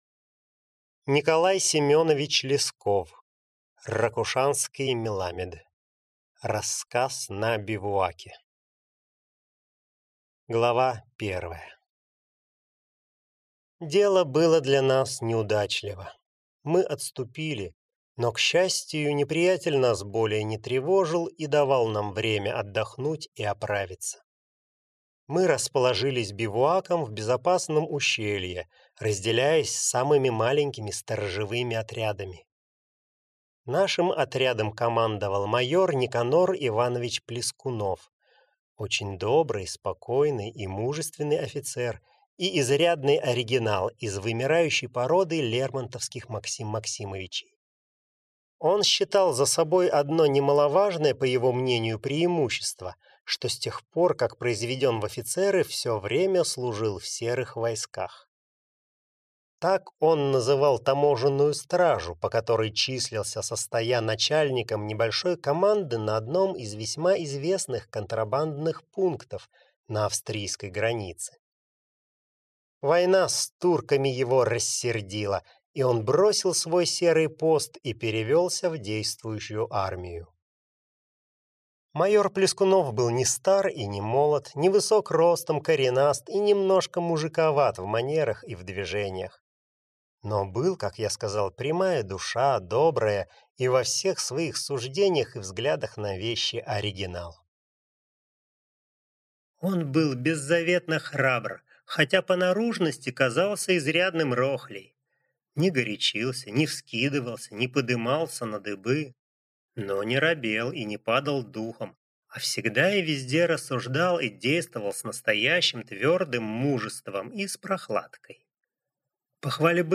Аудиокнига Ракушанский меламед | Библиотека аудиокниг